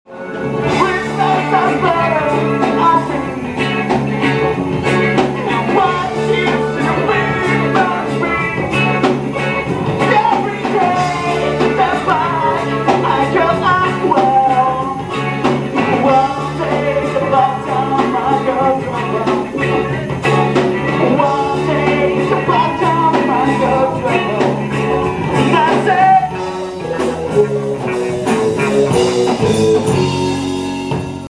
Concerto al "Conca Verde" di Amblar 7/1/2006